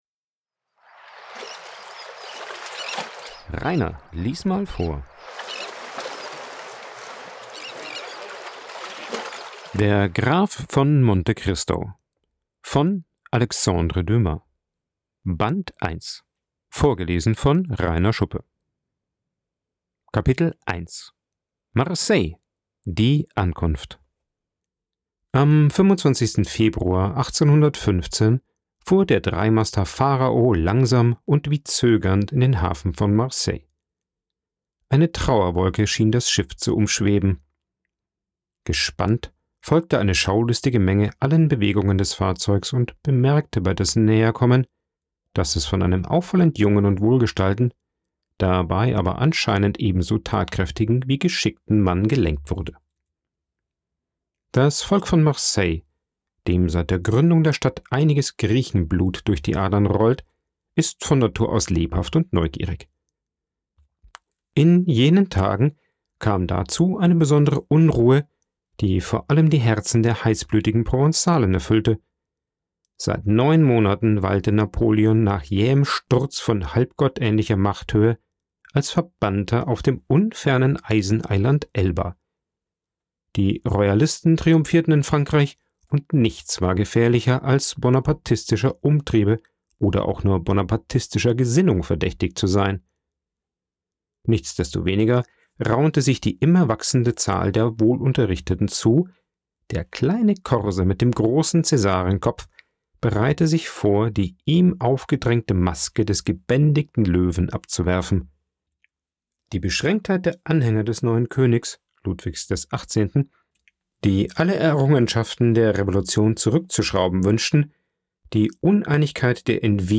aufgenommen und bearbeitet im Coworking Space Rayaworx, Santanyí, Mallorca.